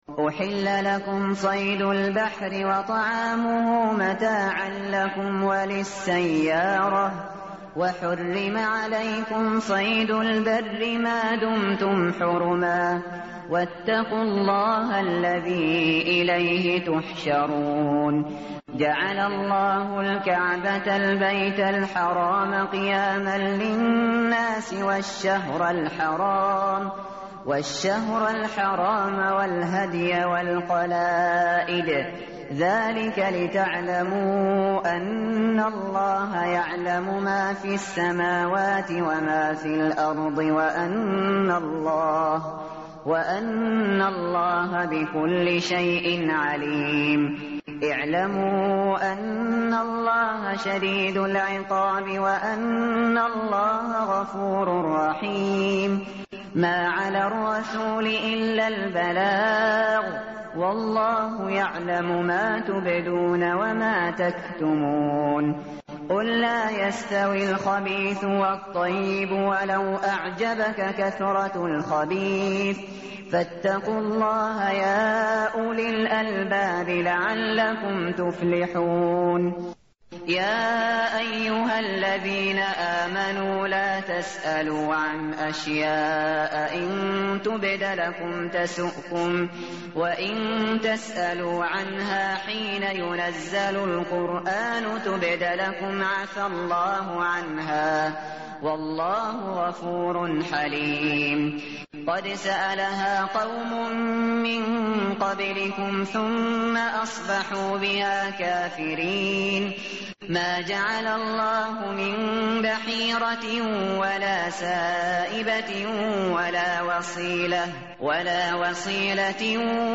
tartil_shateri_page_124.mp3